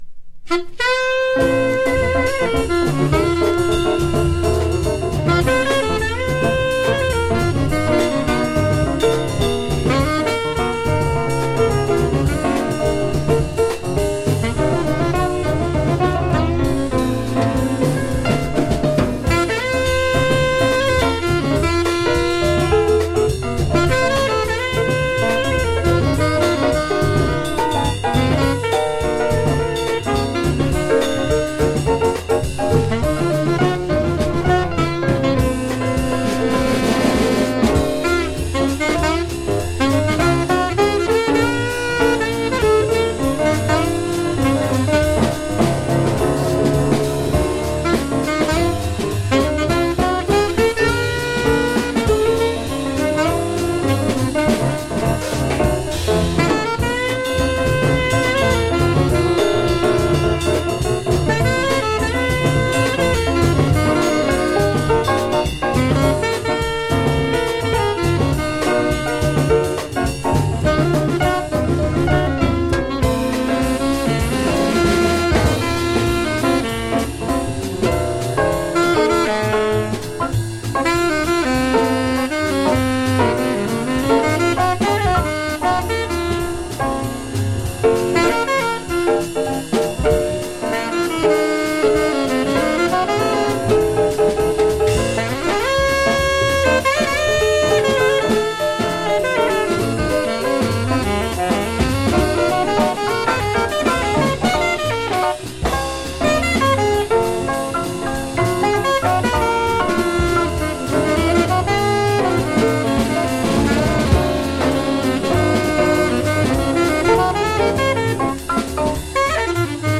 富山県高岡市におけるライヴ録音です。
ピアノトリオ、またはサックスが入ってのカルテットでの演奏です。